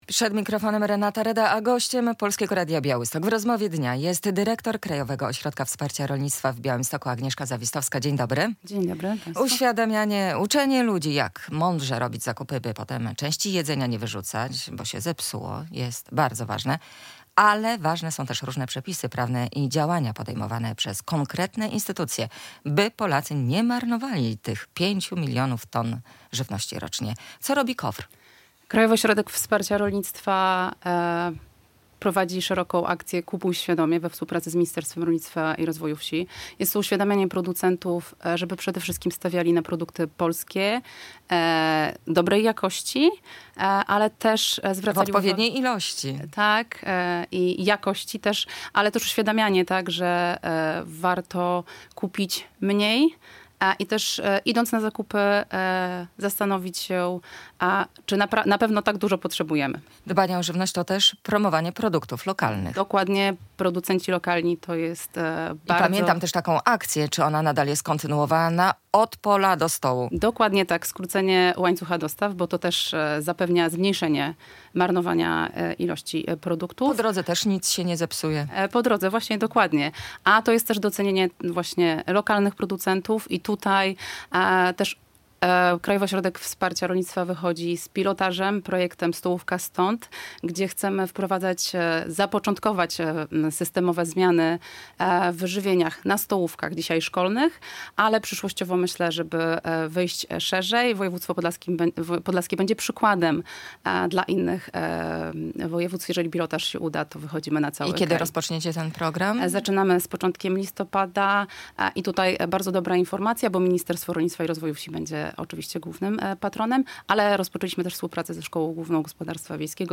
Radio Białystok | Gość | Agnieszka Zawistowska - dyrektor Krajowego Ośrodka Wsparcia Rolnictwa w Białymstoku